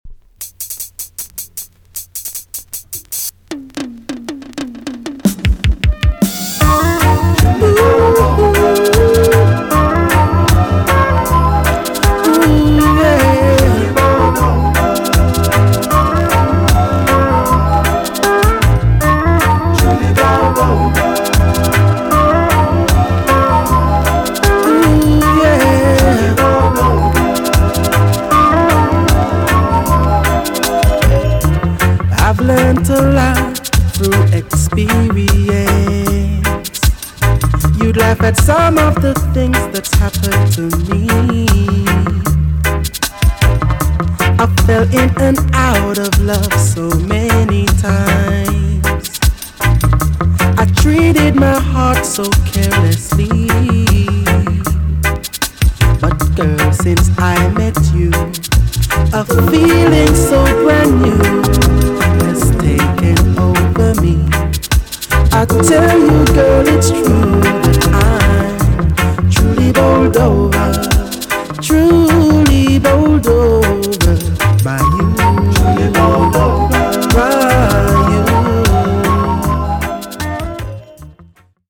TOP >LOVERS >12 inch , DISCO45
EX- 音はキレイです。